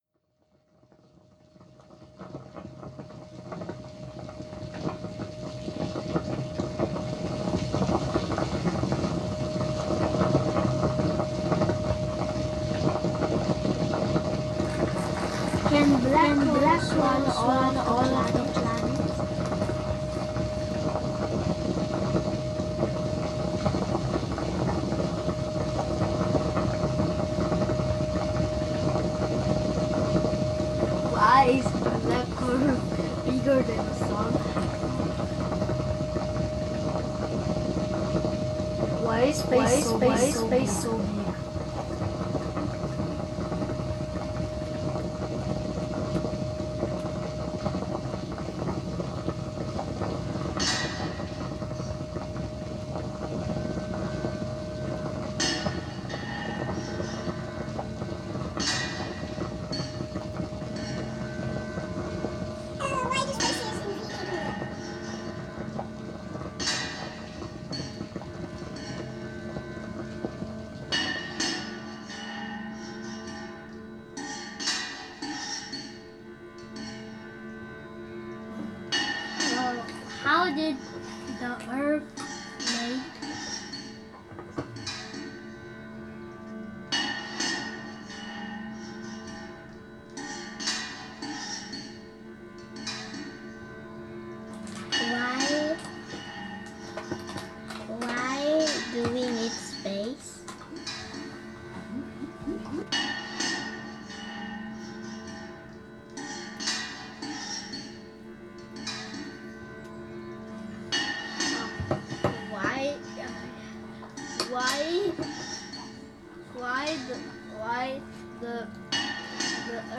at TV Control Center, celebrating 30 years of collaboration.